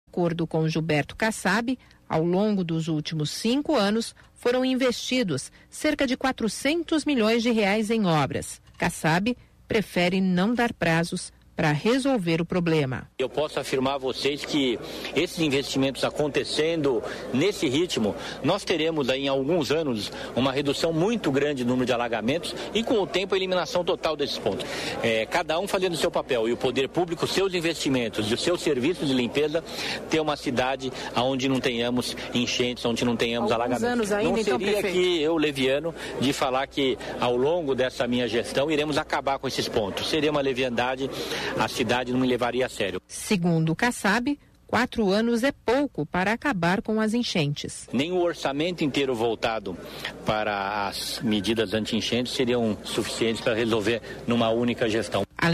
Ouça o que o prefeito Gilberto Kassab (DEM) disse em entrevista